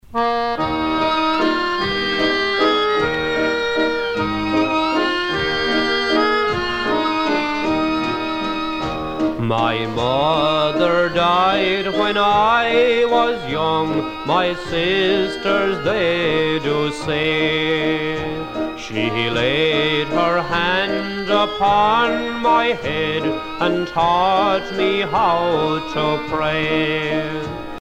danse : valse lente